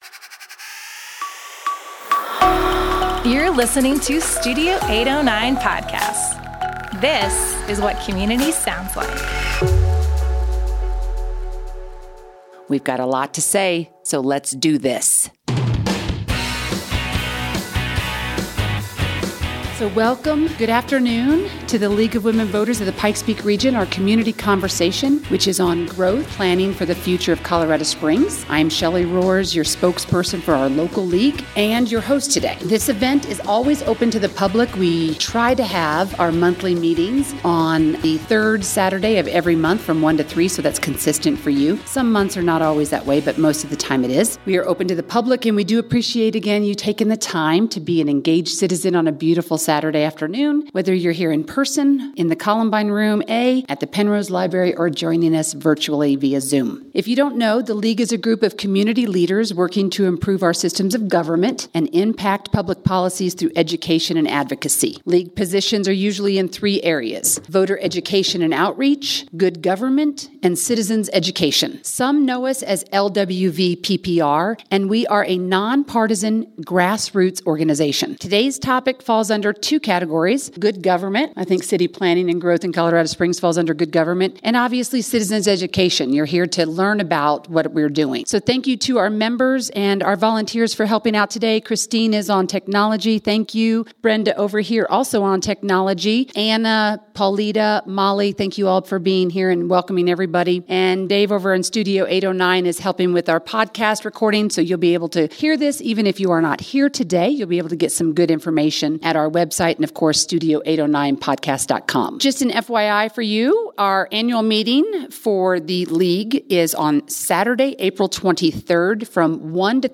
Two city planners for the City of Colorado Springs share highlights about the city’s new comprehensive plan and proposed zoning updates. These are called PlanCOS (the newest comprehensive plan) and RetoolCOS (a major update to our zoning ordinance). Their remarks are from the March 19, 2022 monthly meeting of the League of Women Voters of the Pikes Peak Region.